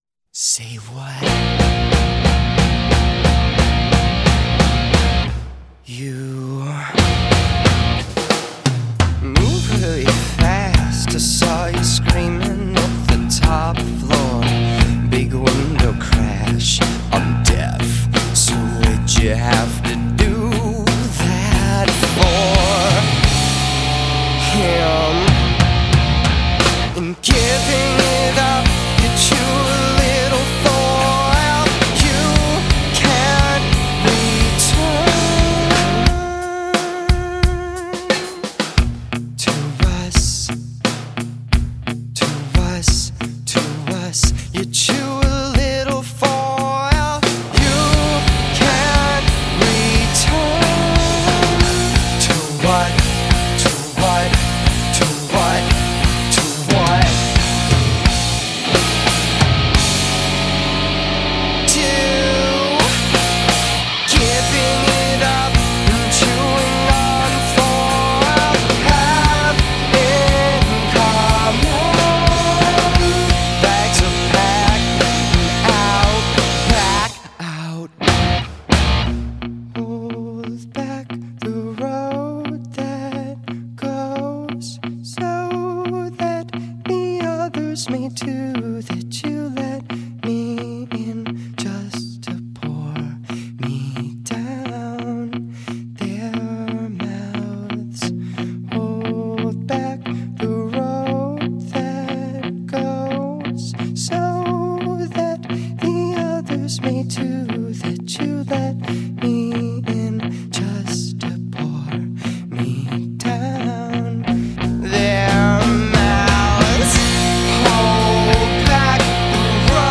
It’s pretty much 5-6 songs crammed into four minutes.